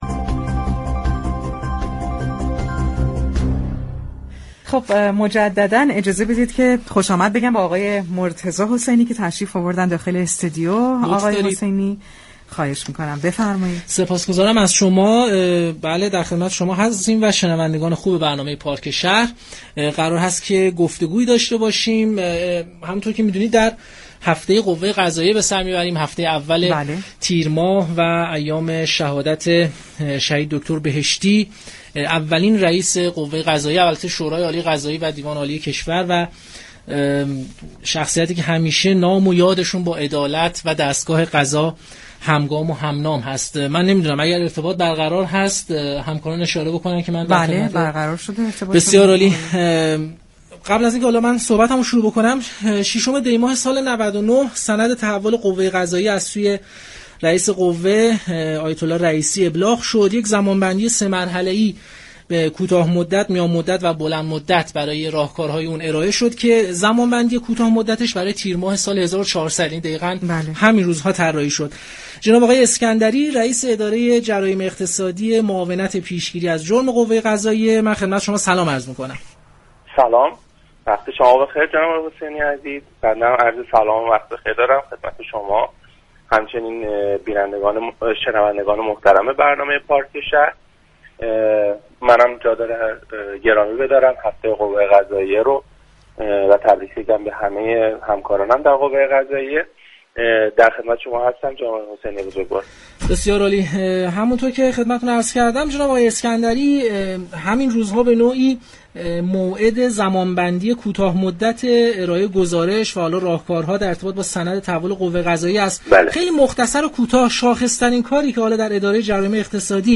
به گزارش پایگاه اطلاع رسانی رادیو تهران، علی اسكندری رئیس اداره پیشگیری از جرایم اقتصادی قوه قضاییه به مناسبت هفته قوه قضاییه و شهادت دكتر بهشتی در گفتگو با پارك شهر رادیو تهران درباره تاسیس این اداره در قوه قضاییه گفت: تولد اداره پیشگیری از جرایم اقتصادی محصول شش ماهه دوم سال 99 است و بر اساس چارت جدیدی كه برای قوه قضاییه در نظر گرفته شد مجموعه معاونت پیشگیری از جرایم به وجود آمد و اداره پیشگیری از جرایم اقتصادی زیرمجموعه این اداره شروع به فعالیت كرد.